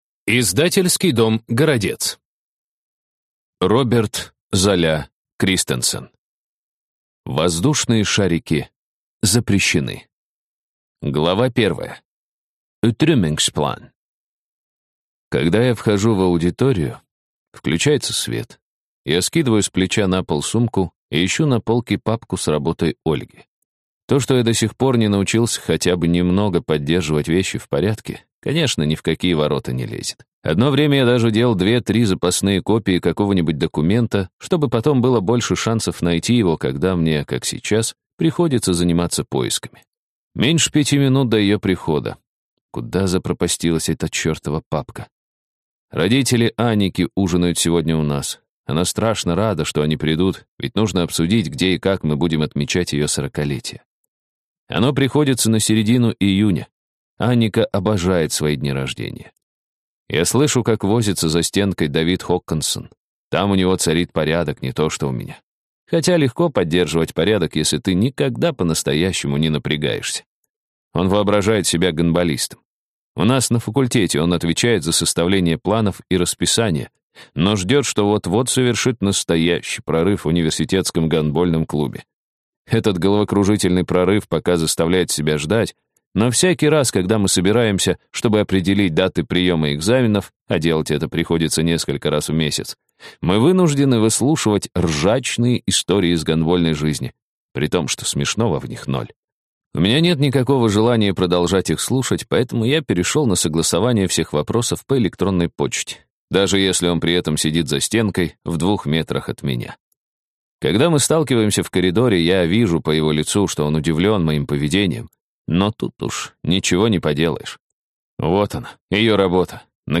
Аудиокнига Воздушные шарики запрещены | Библиотека аудиокниг